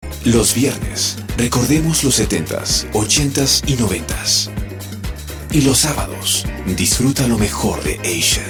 Locución de eventos en los medios de comunicación ó narraciones.
La locución es genial
Kein Dialekt